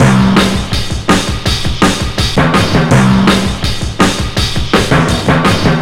Index of /90_sSampleCDs/Zero-G - Total Drum Bass/Drumloops - 2/track 32 (165bpm)